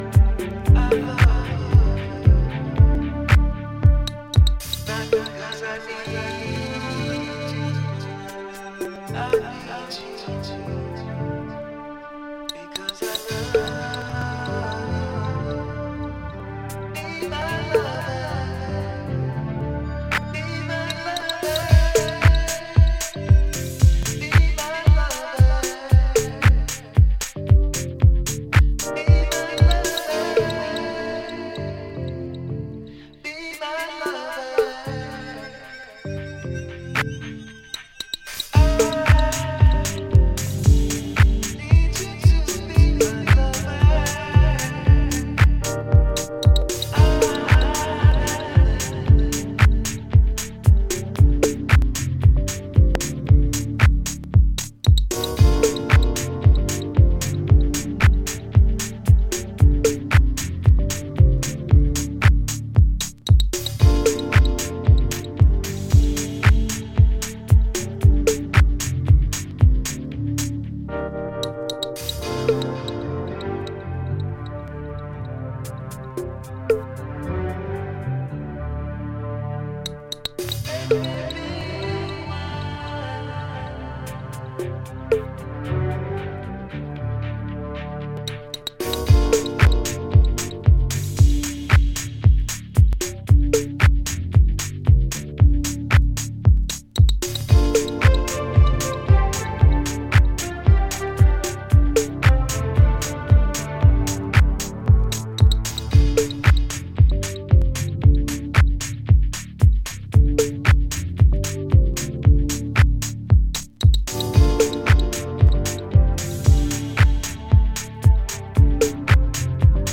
柔らかい浮遊コードやスペーシーなシンセを駆使しながら、じっくりとビートダウン・ハウスを繰り広げています。